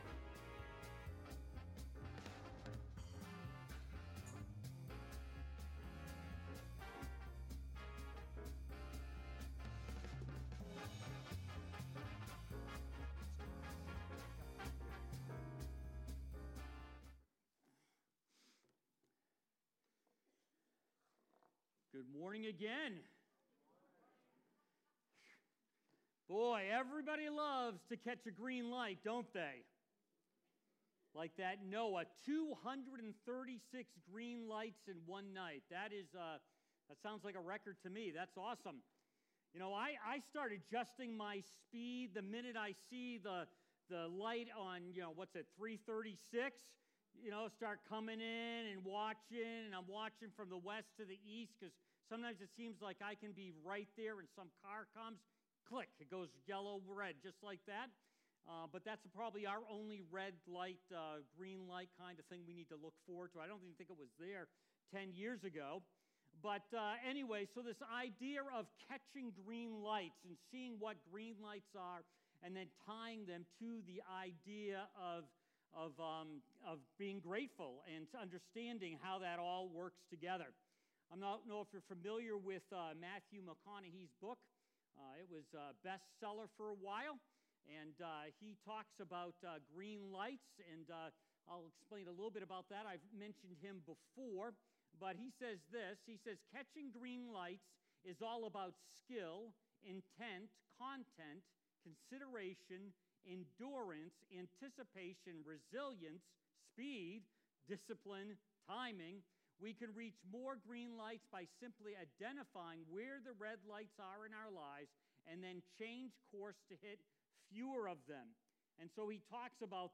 Join us for a stand-alone message, "Grateful". We'll see what an attitude of gratitude could unlock in our lives.